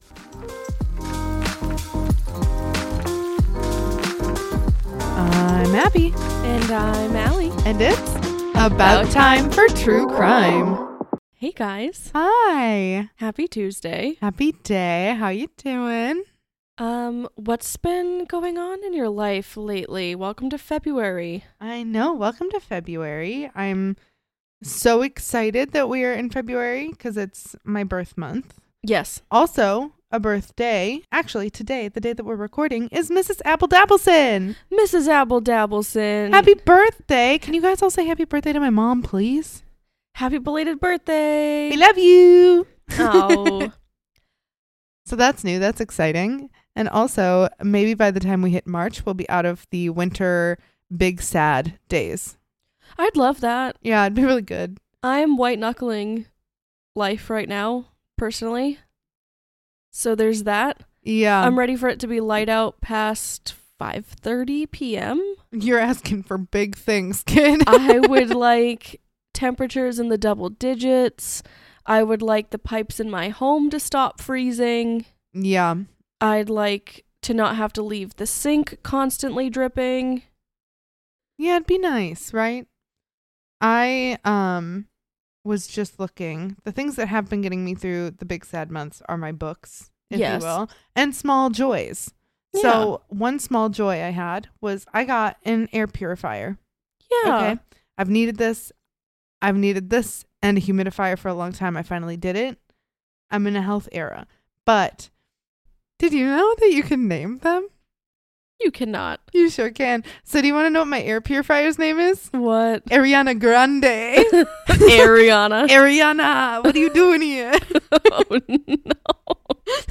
Its about time you heard a true crime podcast from two educated women in the field.
Do you enjoy deadpan, sarcastic humor while digging into gruesome topics?